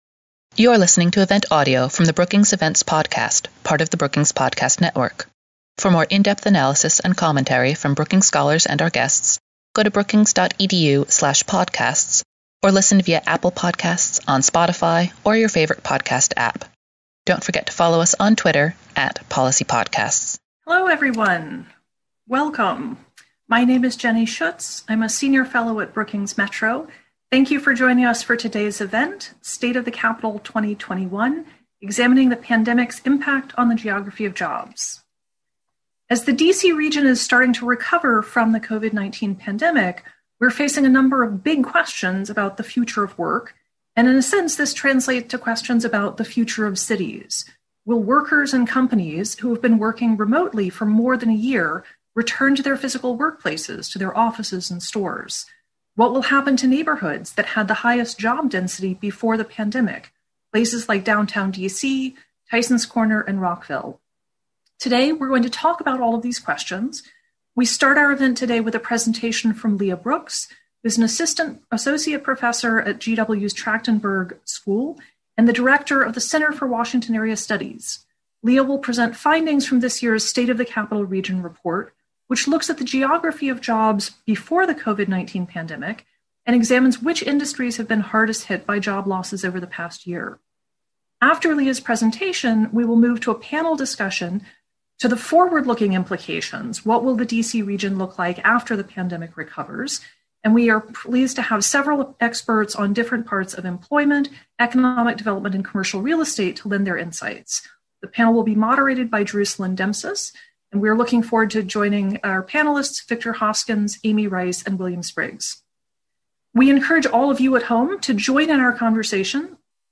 An expert panel followed, discussing some of the challenges faced by workers, employers, property owners, and policymakers.